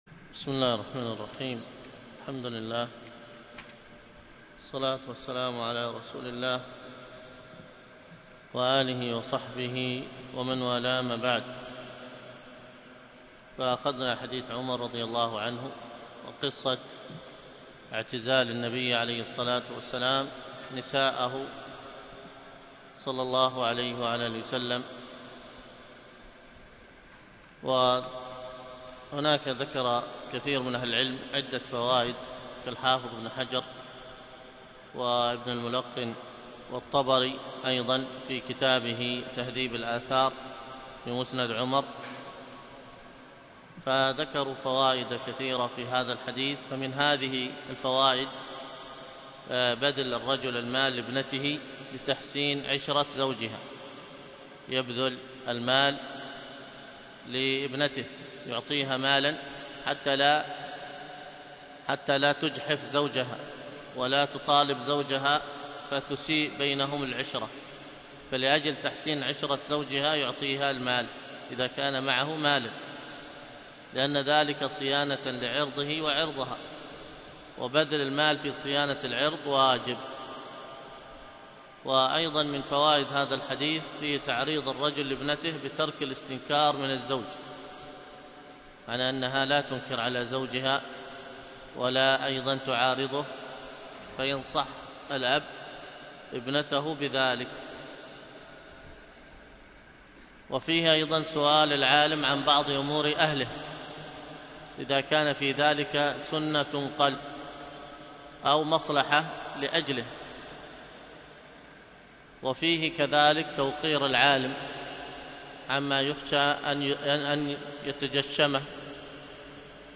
الدرس في كتاب النكاح والطلاق 11، ألقاها